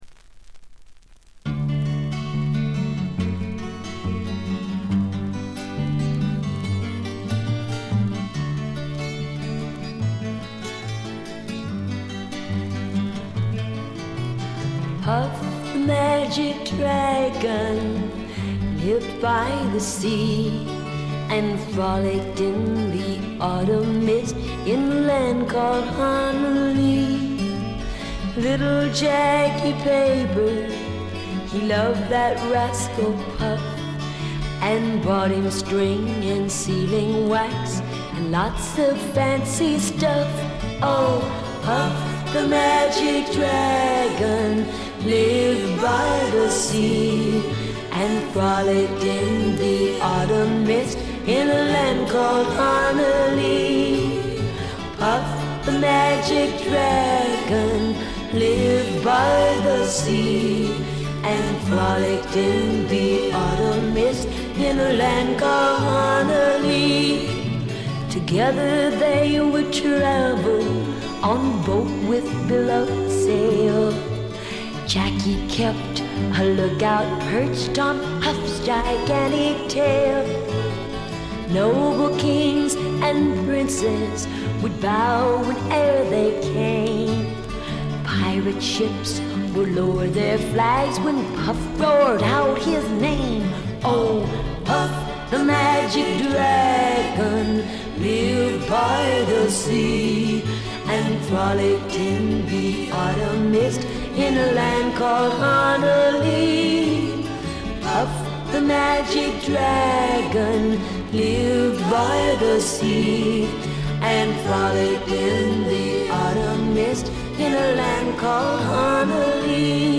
全体に細かなチリプチ、バックグラウンドノイズが出ていますが気になるのはこのB1ぐらい。
美しいフォーク・アルバムです。
最初期のモノラル盤。
試聴曲は現品からの取り込み音源です。